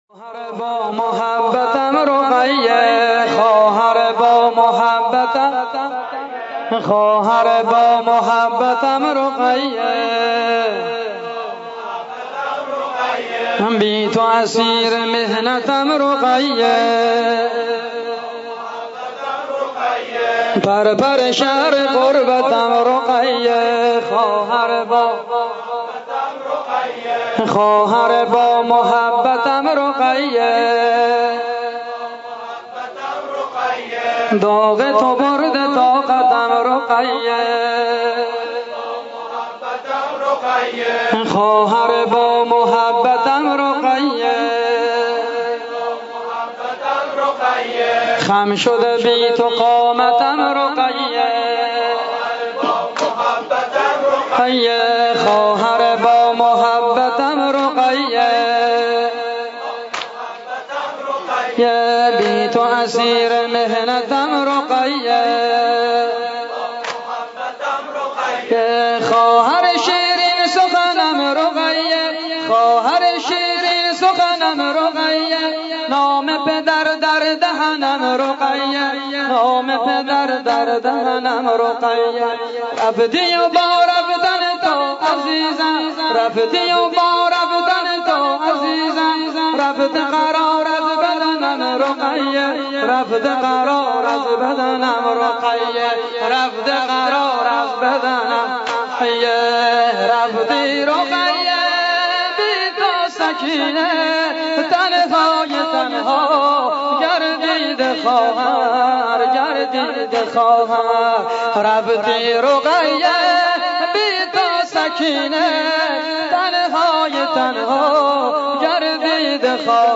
نوحه سنتی زنجیر زنی با سبک جنوبی بسیار زیبا برای حضرت رقیه (س) -( خواهر با محبتّم رقیه بی تو اسیر محنتم رقیه )